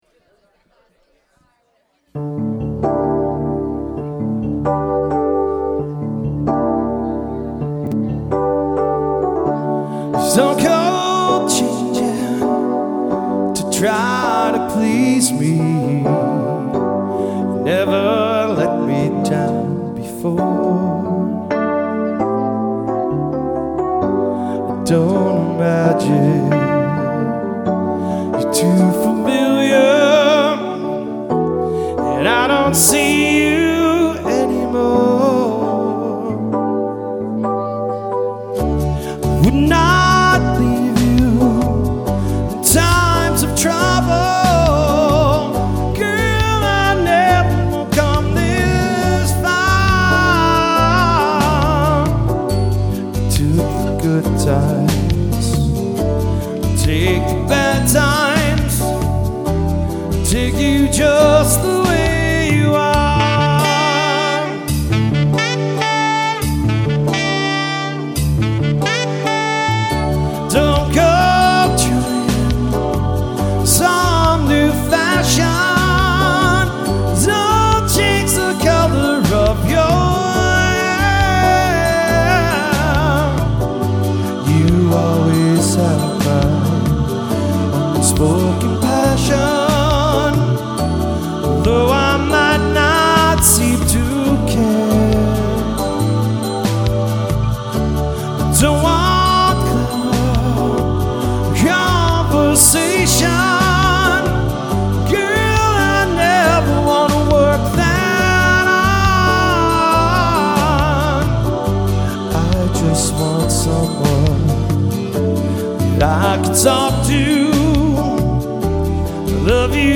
Acoustic/electric guitars and one singer.
(guitar, vocals with backing tracks)